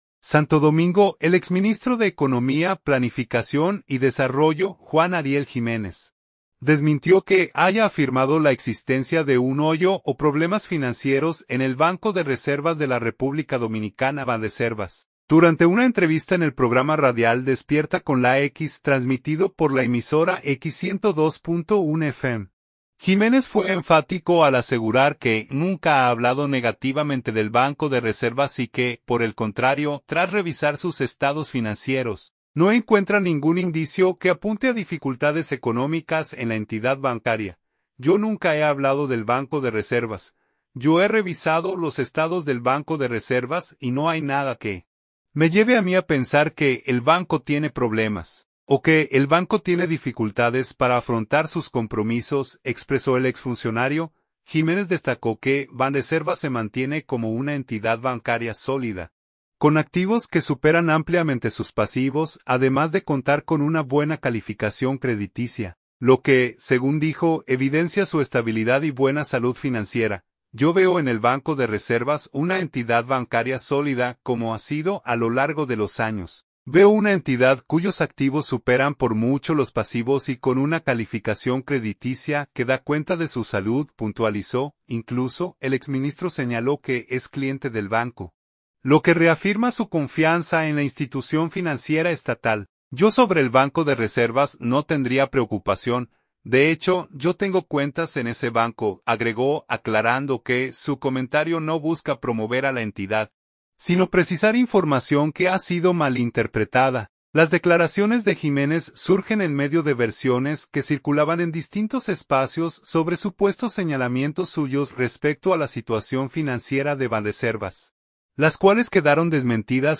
Durante una entrevista en el programa radial “Despierta con la X”, transmitido por la emisora X 102.1 FM, Jiménez fue enfático al asegurar que nunca ha hablado negativamente del Banco de Reservas y que, por el contrario, tras revisar sus estados financieros, no encuentra ningún indicio que apunte a dificultades económicas en la entidad bancaria.